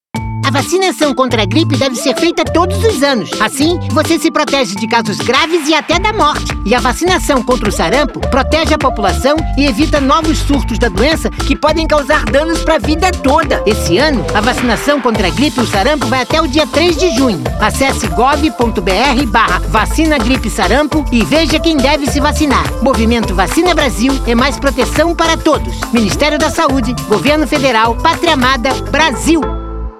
Spot - Importância da Vacinação - Gripe e Sarampo